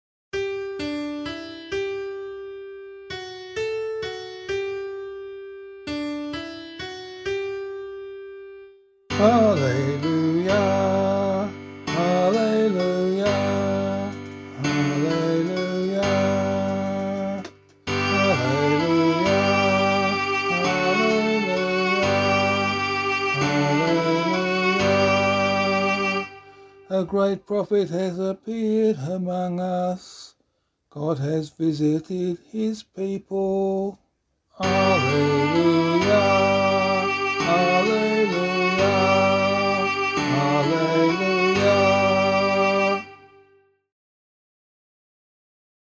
040 Ordinary Time 6 Gospel B [LiturgyShare F - Oz] - vocal.mp3